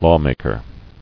[law·mak·er]